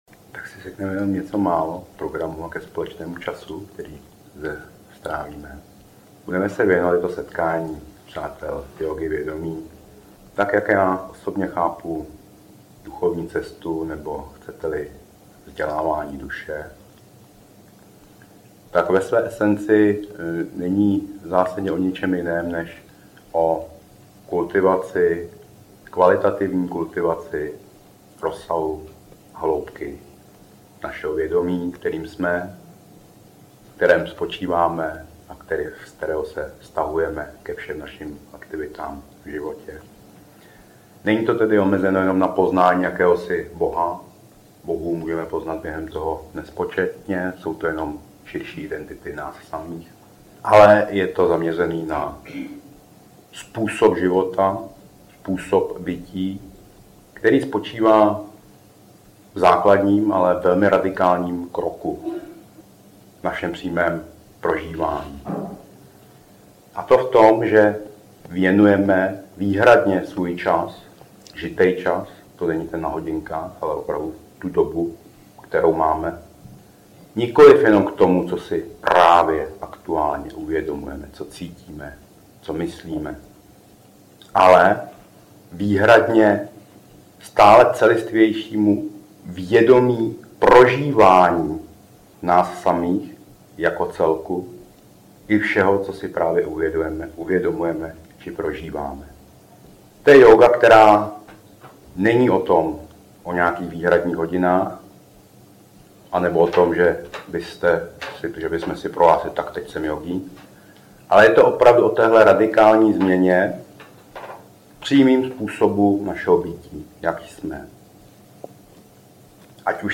Audiokniha
Audio nahrávky ze setkání jógy vědomí – Chodsko – září 2018. V těchto nahrávkách najdete tato témata a cvičení: prostorovost vědomí, čidakáša, kvality vědomí, vnitřní božství -Triglav, přetnutí mechanizmu ega, jak vzniká vše zvučením, vnímání rostlin a zvířat, subjektivní kvalitativní vnímání názorů, strach ze skutečné svobody, anaerobní dýchání, éteričnost vědomí, prána jako dech vědomí, pravý smysl harakiri, co je to sóma, praktické důsledky jógy vědomí, kvalitativní trávení času, celistvění, vytmavení, jazykové struktury a další. Nejedná se o studiovou nahrávku.